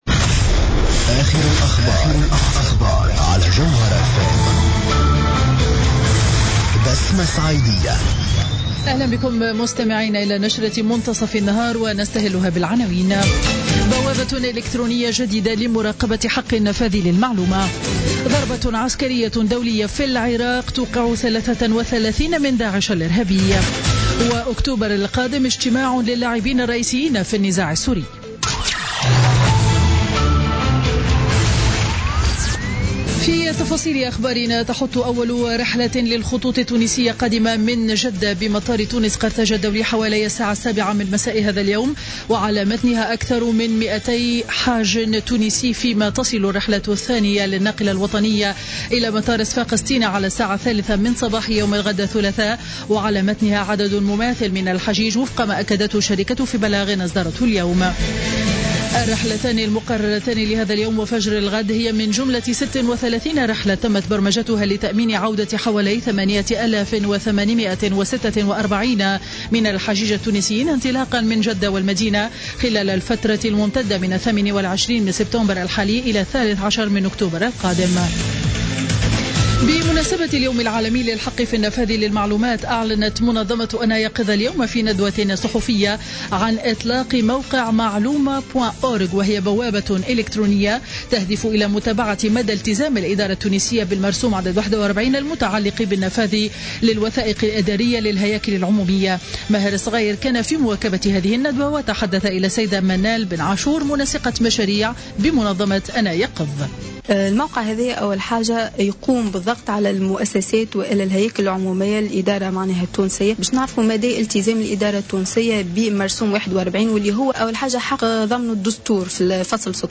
نشرة أخبار منتصف النهار ليوم الاثنين 28 سبتمبر 2015